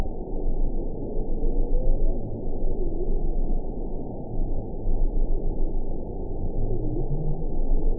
event 921156 date 05/02/24 time 01:34:06 GMT (1 year, 1 month ago) score 7.59 location TSS-AB10 detected by nrw target species NRW annotations +NRW Spectrogram: Frequency (kHz) vs. Time (s) audio not available .wav